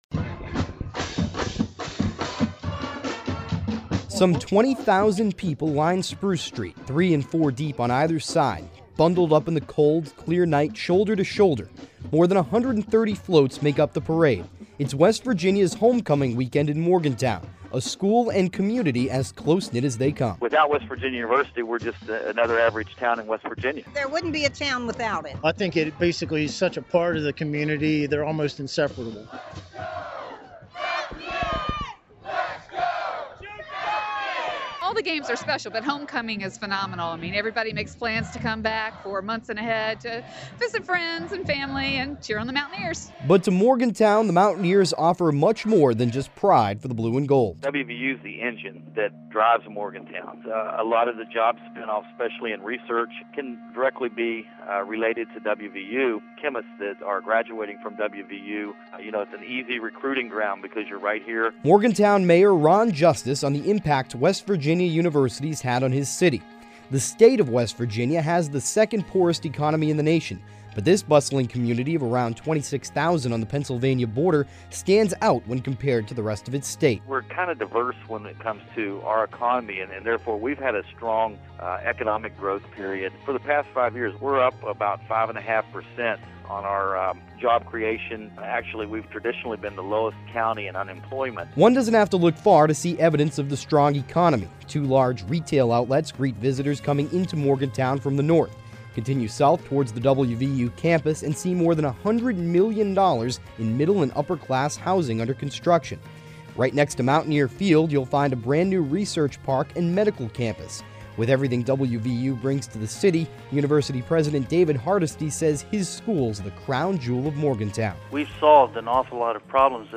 Fourth Place Radio I – Features - Hearst Journalism Awards Program